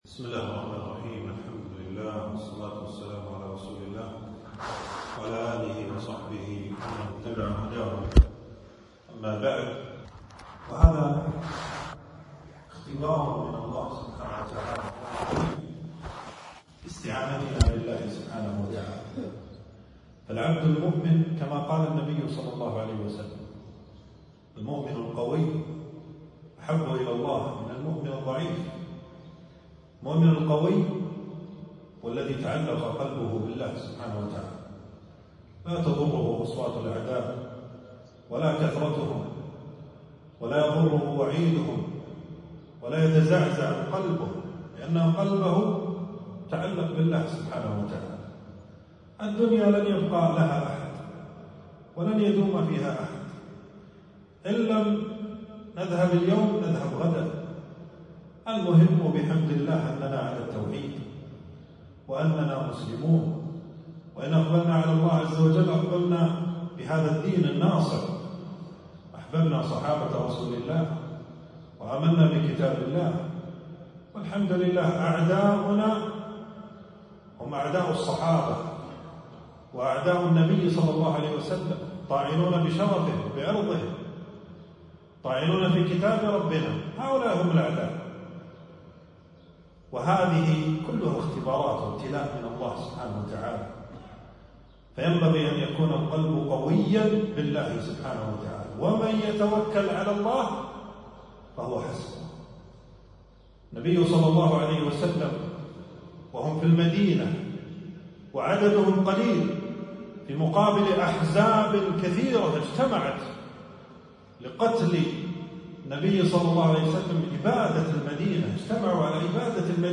تنزيل تنزيل التفريغ كلمة بعنوان: الثبات الثبات عند سماع التفجيرات.
في مسجد أبي سلمة بن عبدالرحمن.